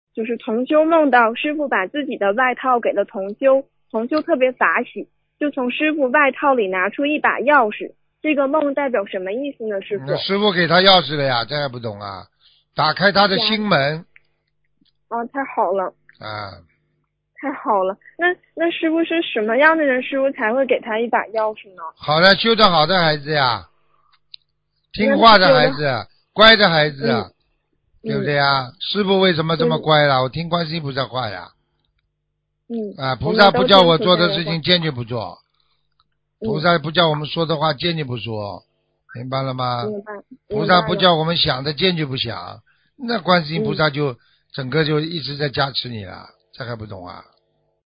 2. 录音文字整理尽量保持与原录音一致。但因对话交流带有语气，文字整理不可能完全还原情境。